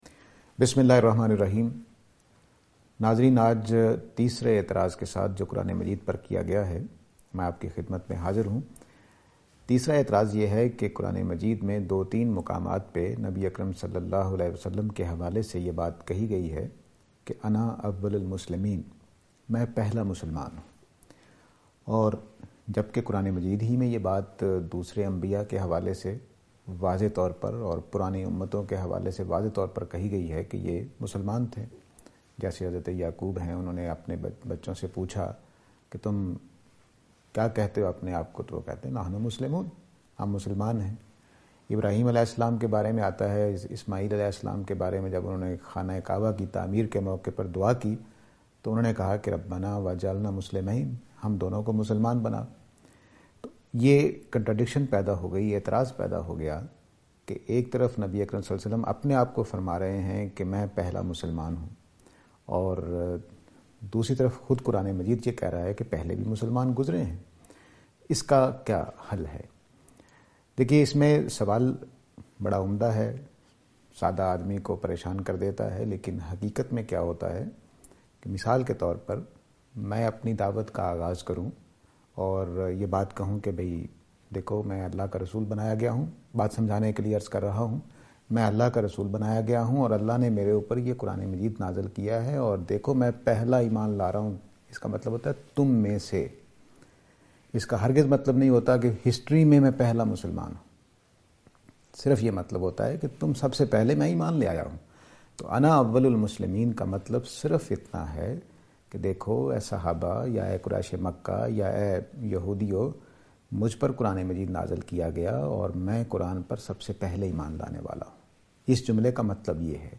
This lecture will present and answer to the allegation "Prophet (P B U H) is Muslim first or something else?".